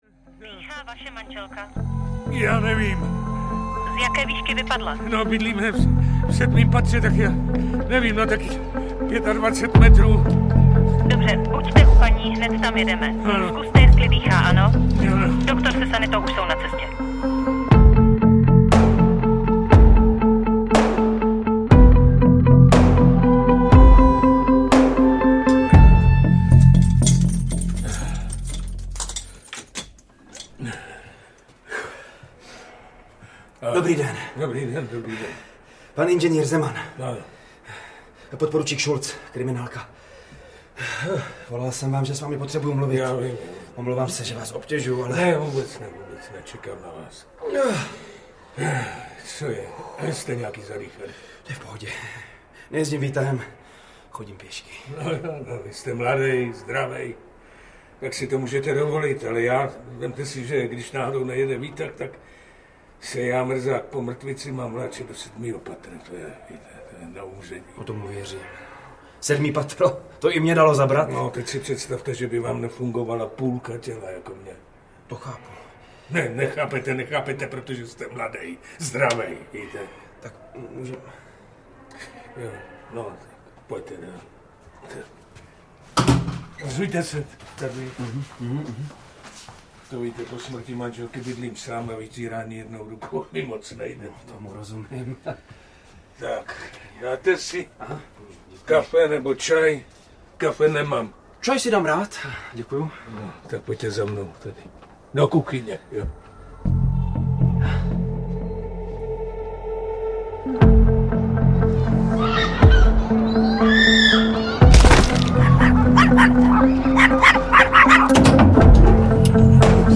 Okno smrti audiokniha
• InterpretViktor Preiss, Václav Neužil